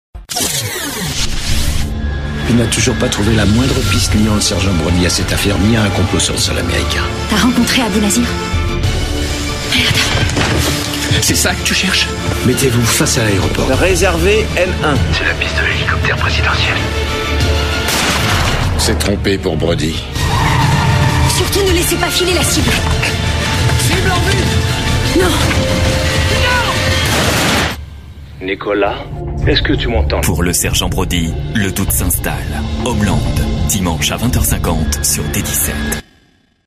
Genre : voix off.